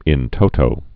(ĭn tōtō)